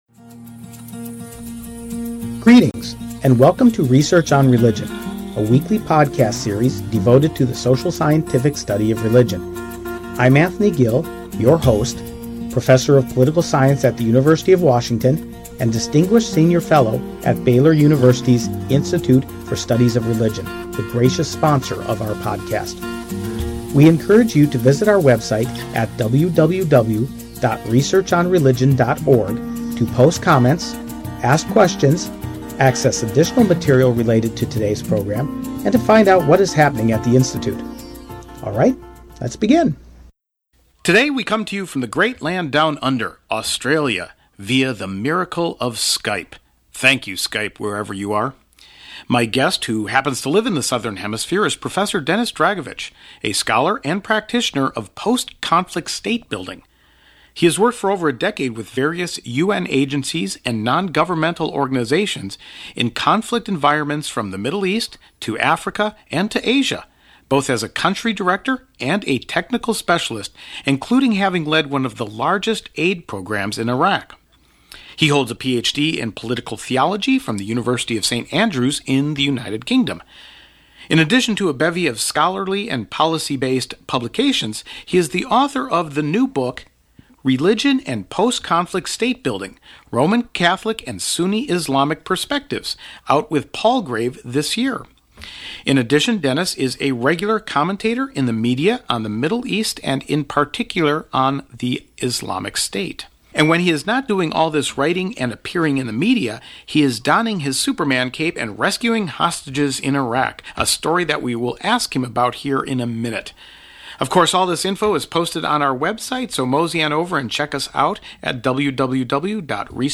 Interview with the author